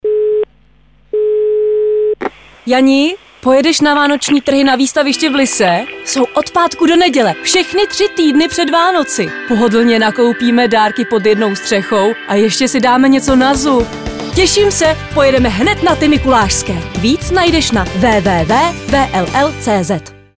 Umím: Voiceover